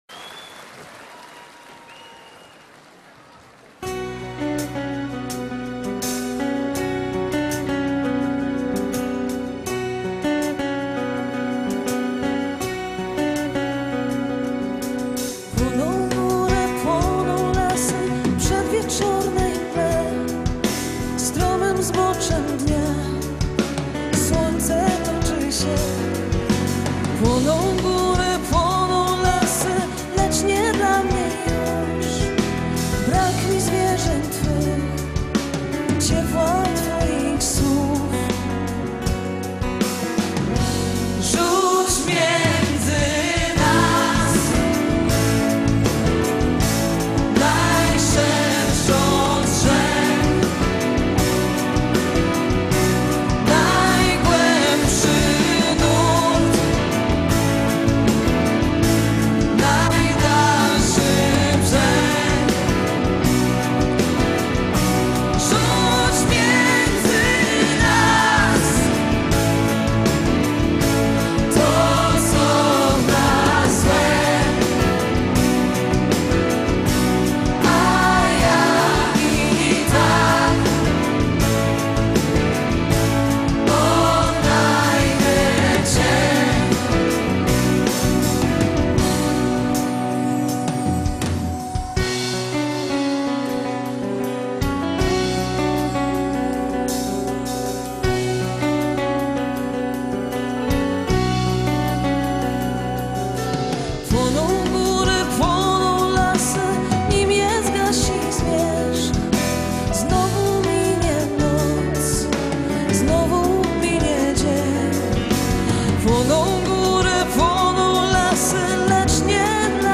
Другой вариант, менее известные (live !)